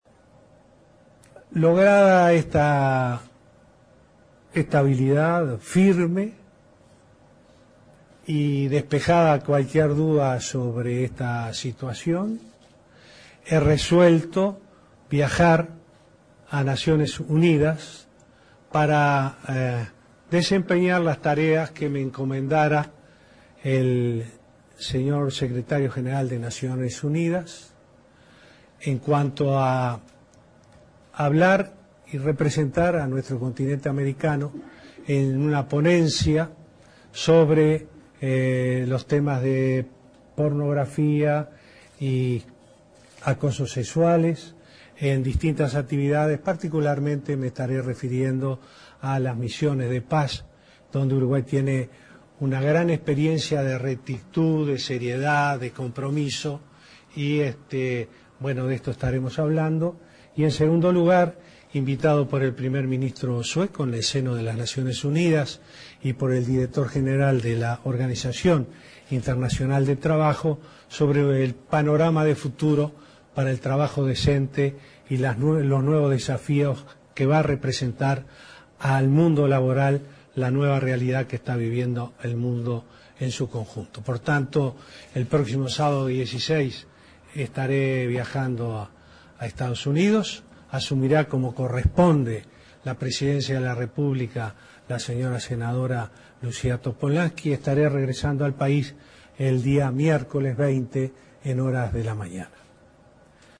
El presidente de la República, Tabaré Vázquez, dedicó la conferencia de prensa posterior al Consejo de Ministros para referirse a la renuncia presentada por el vicepresidente, Raúl Sendic, ante el Plenario del Frente Amplio.